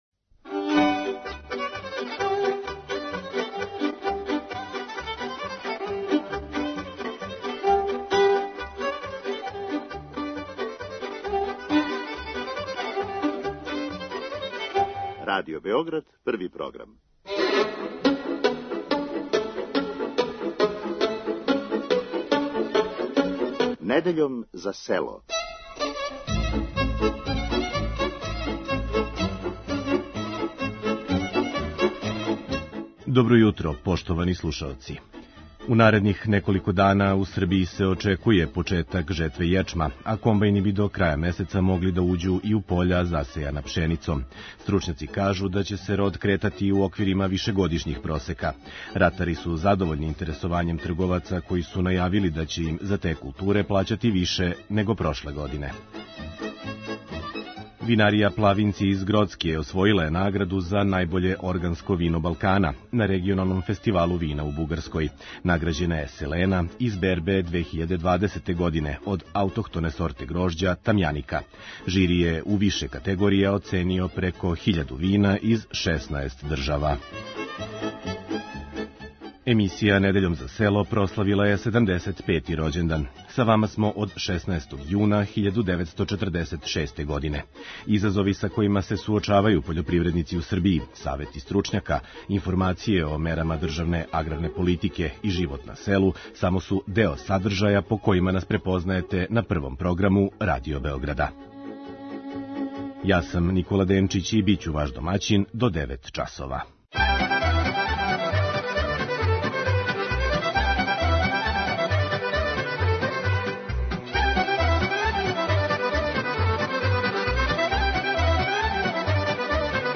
Уз остале теме из области пољопривреде у емисији Вас чека и традиционална народна музика из свих делова Србије.